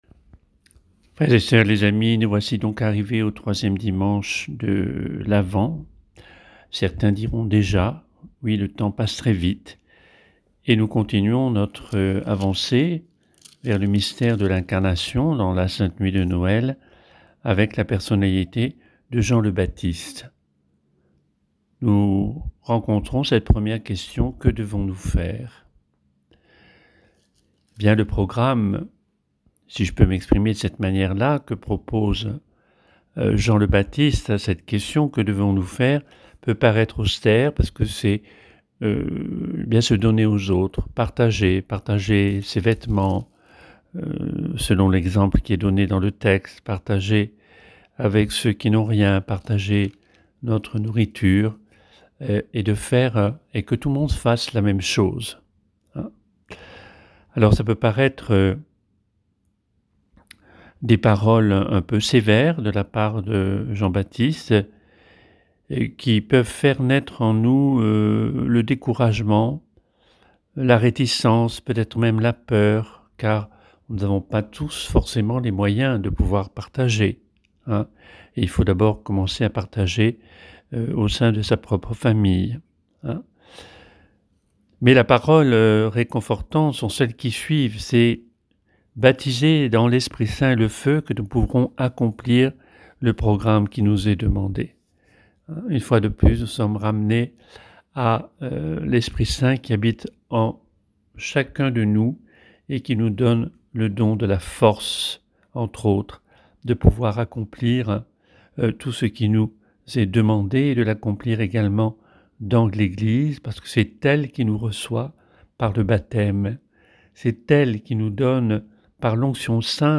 Méditation